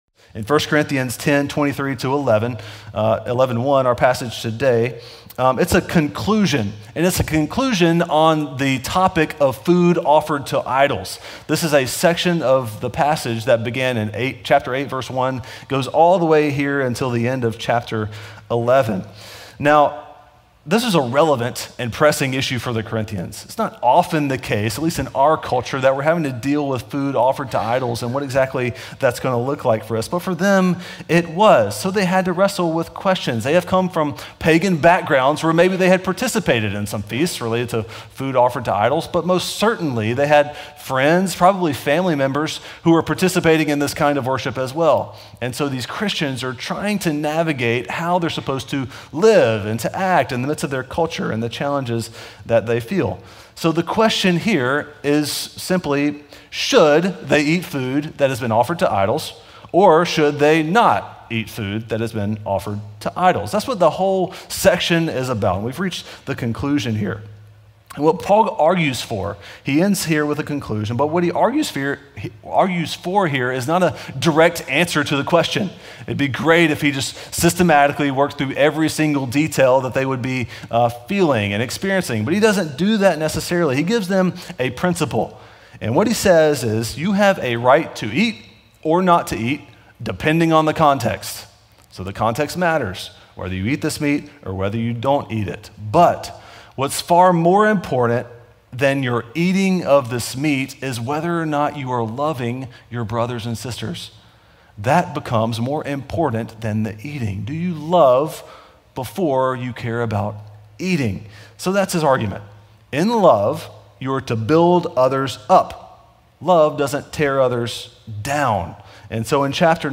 Weekly sermons from Beacon Community Church in Belmont, MA.